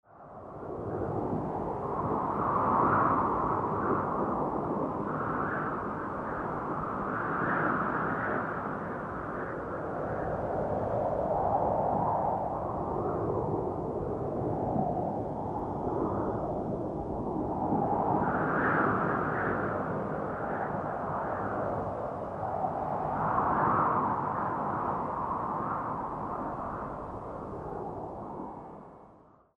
VIENTO SOPLANDO NORMAL
Tonos EFECTO DE SONIDO DE AMBIENTE de VIENTO SOPLANDO NORMAL
Viento_soplando_normal.mp3